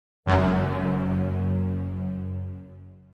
suspense pan Meme Sound Effect
This sound is perfect for adding humor, surprise, or dramatic timing to your content.
suspense pan.mp3